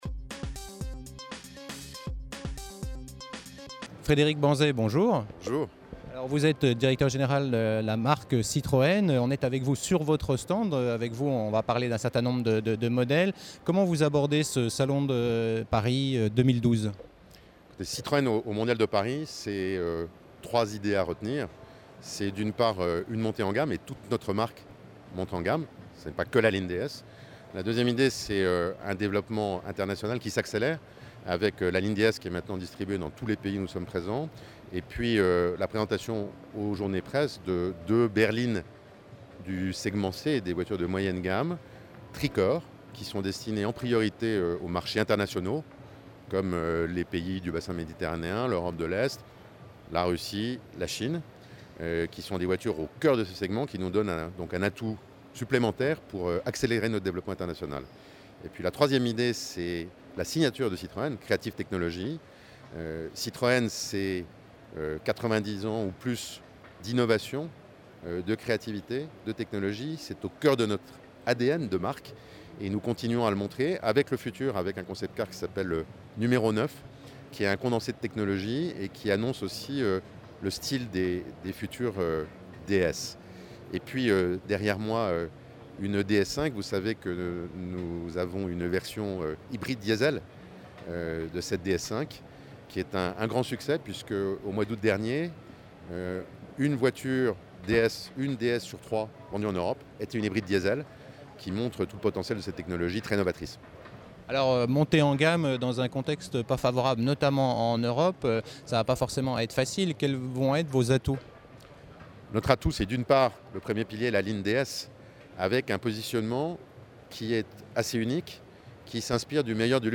Mondial de l'Automobile 2012 à Paris avec Citroën : nouveautés et perspectives